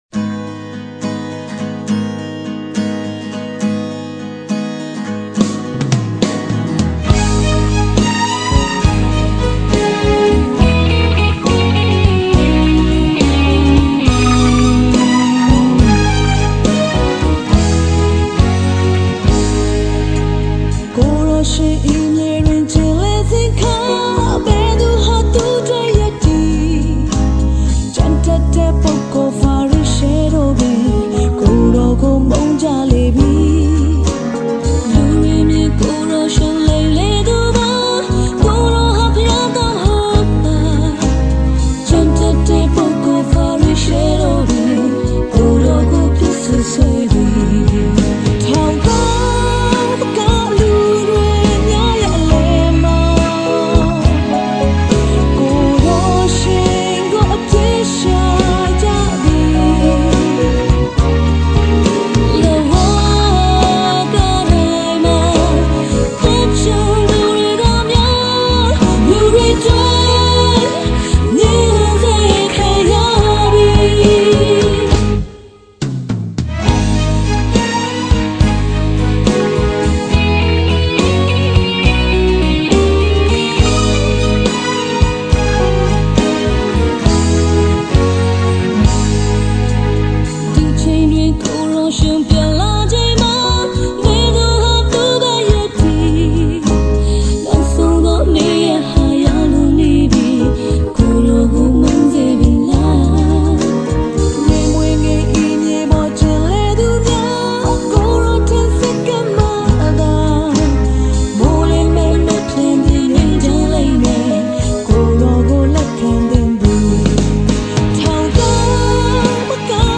Gospel Songs